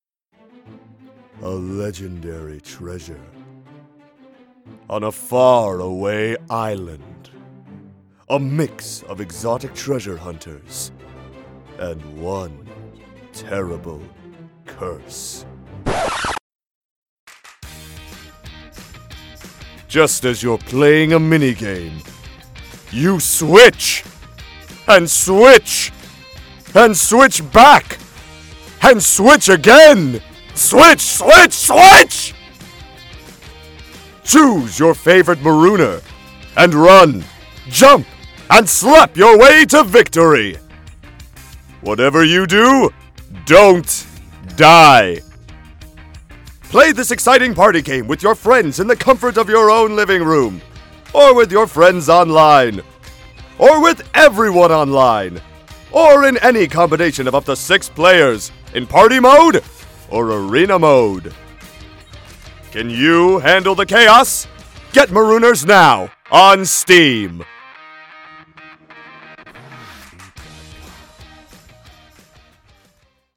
Marooners - Quick Trailer With Voice Over and Music.mp3